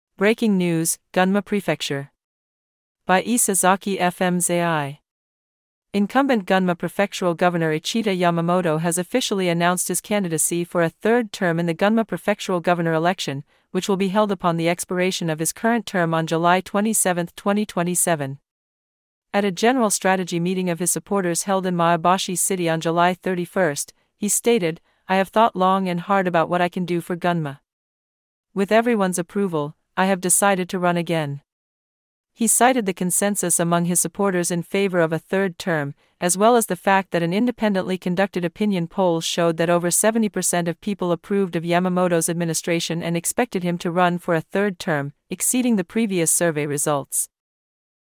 Audio Channels: 1 (mono)